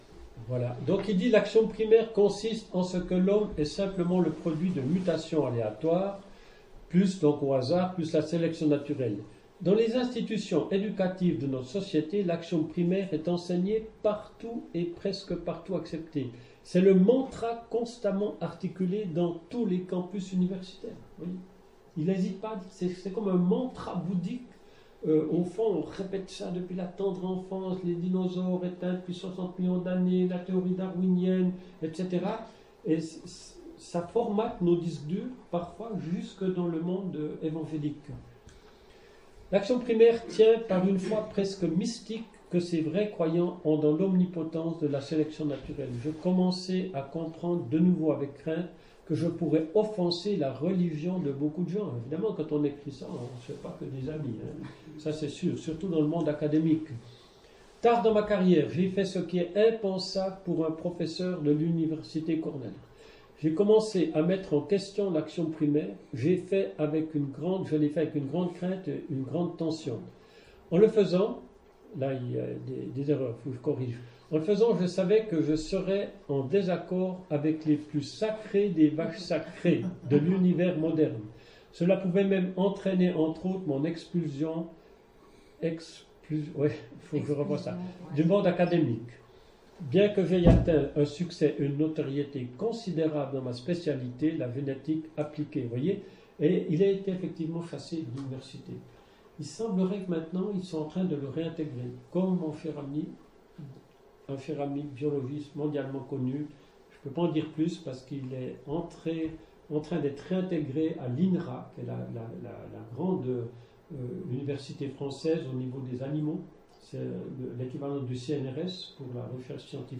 [Chapelle de l’Espoir] - Conférence "Science et Foi", quatrième et dernière partie